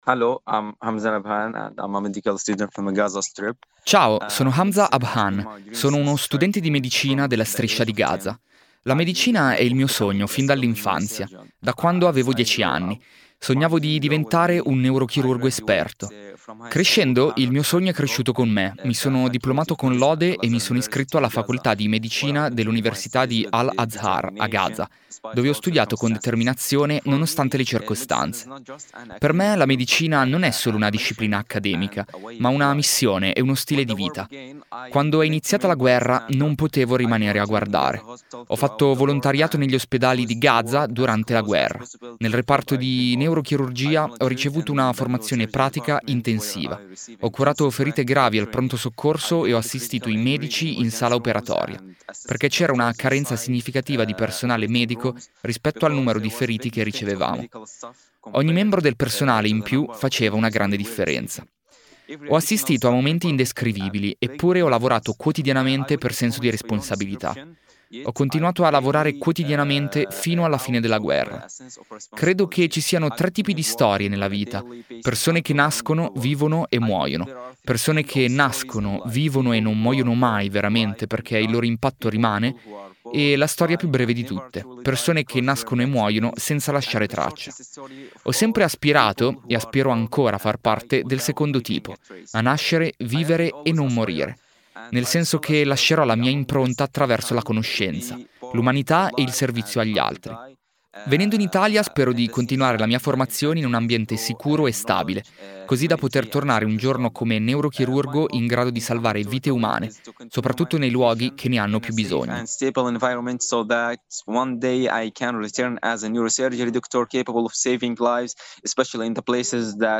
Come sentirete dalle loro voci, ci sperano ancora e fanno di tutto per essere pronti.
Intervista